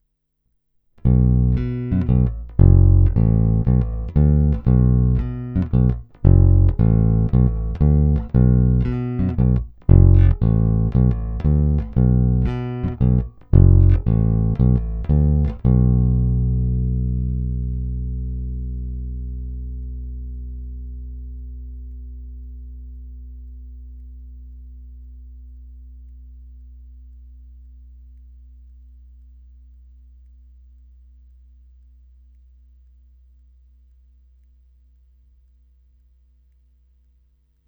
Jestliže zvukový projev lípy je obecně měkčí, temnější, jelikož se jedná o měkké dřevo, snímače tento projev upozadily, zvuk je naprosto klasický průrazně jazzbassový, s pořádnou porcí kousavých středů.
Není-li uvedeno jinak, následující nahrávky jsou provedeny rovnou do zvukové karty, jen normalizovány, jinak ponechány bez úprav.
Tónová clona vždy plně otevřená.
Snímač u krku